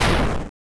bang2.wav